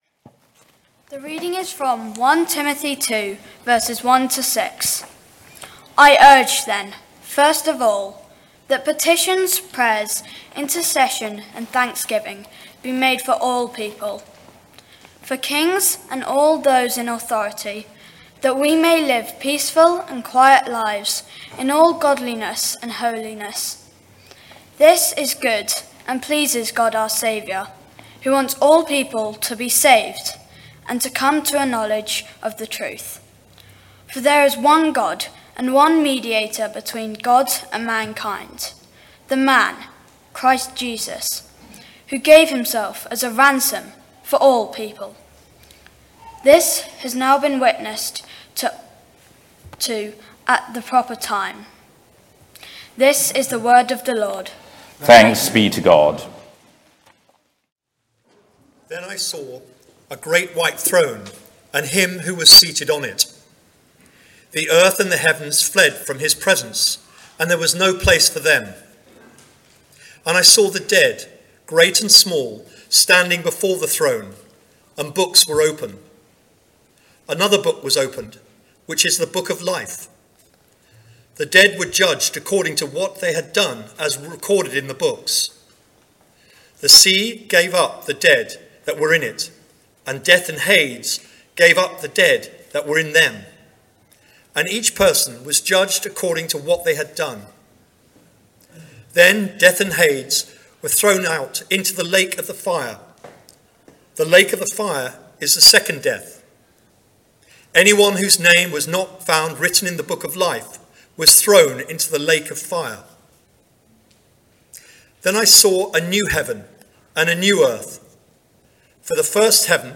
St Mary’s Church Wargrave
Remembrance Sermon